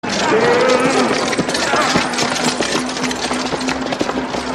• HORSE AND CARRIAGE PASSING BY.wav
HORSE_AND_CARRIAGE_PASSING_BY_OrX.wav